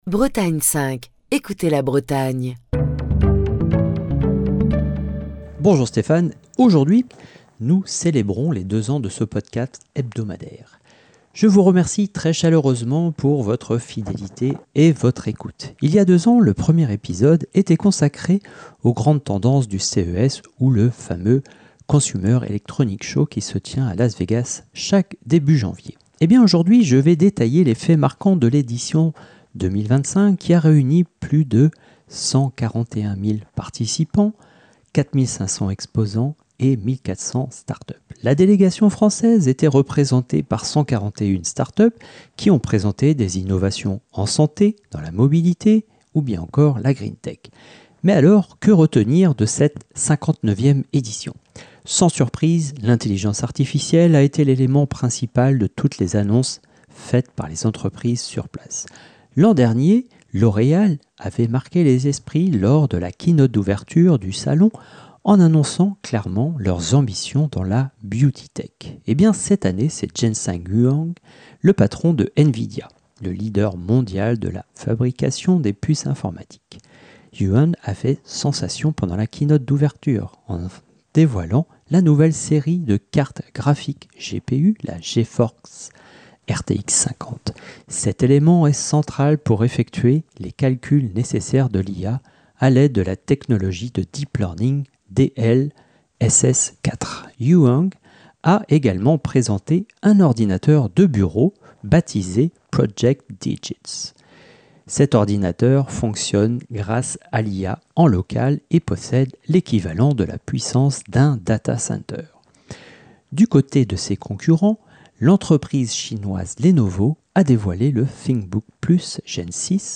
Chronique du 29 janvier 2025.